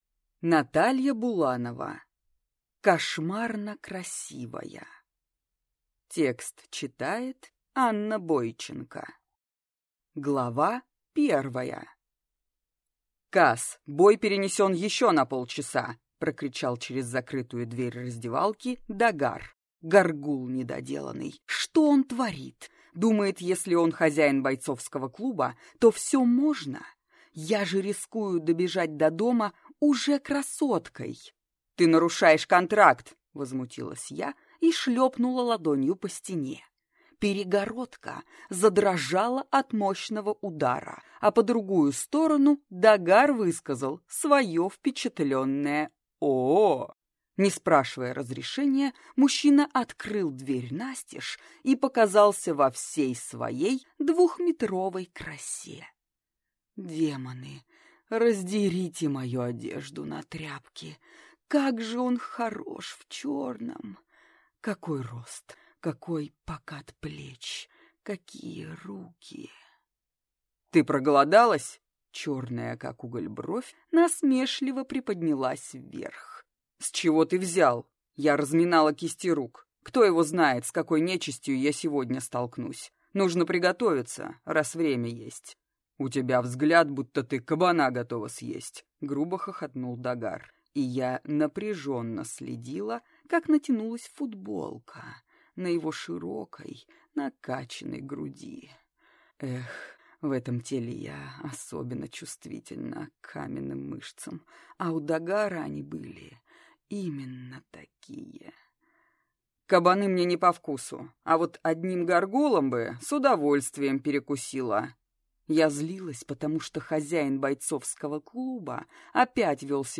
Аудиокнига Кошмарно красивая | Библиотека аудиокниг